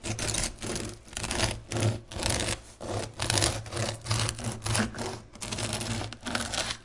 kitchen sounds » bread slicing
描述：slicing fresh bread with bread knife on wooden board
标签： bread breadknife slicing
声道立体声